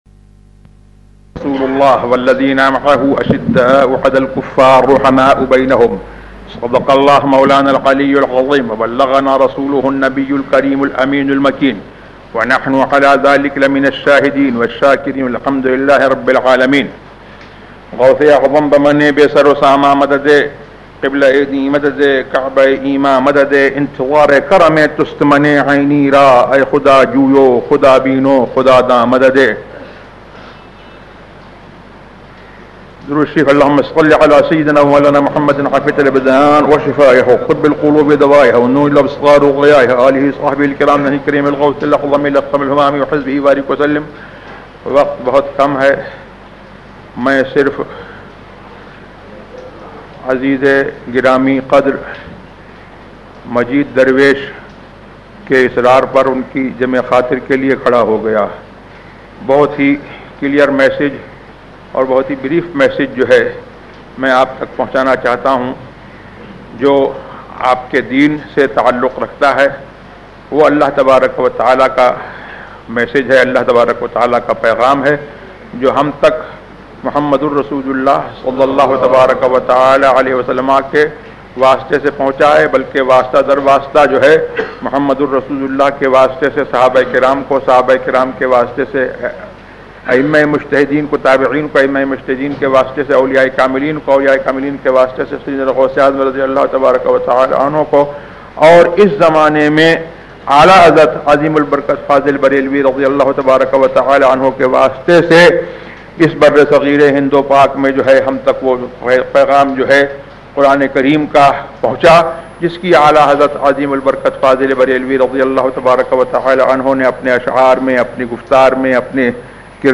اللہ کا پیغام ZiaeTaiba Audio میڈیا کی معلومات نام اللہ کا پیغام موضوع تقاریر آواز تاج الشریعہ مفتی اختر رضا خان ازہری زبان اُردو کل نتائج 1023 قسم آڈیو ڈاؤن لوڈ MP 3 ڈاؤن لوڈ MP 4 متعلقہ تجویزوآراء